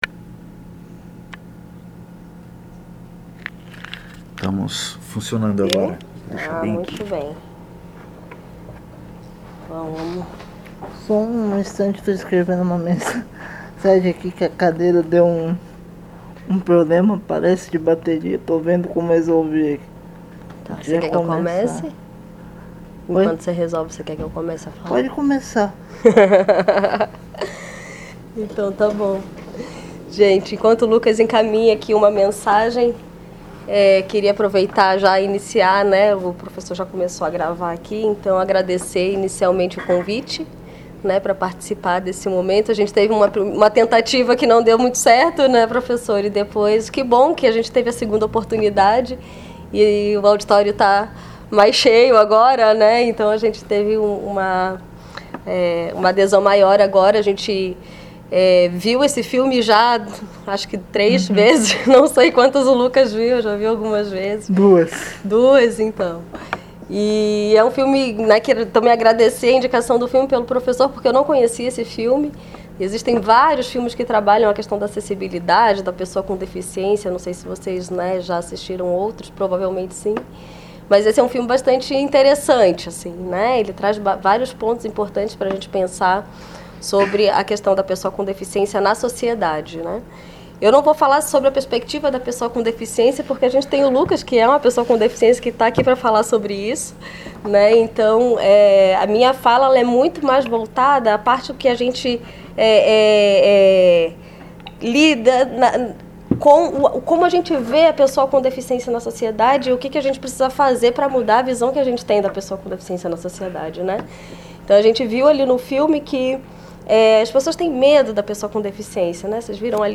Comentários dos(as) debatedores(as) convidados(as)
na sessão de exibição e debate do filme “37 segundos”
realizada em 11 de maio de 2023 no Auditório Elke Hering da Biblioteca Central da UFSC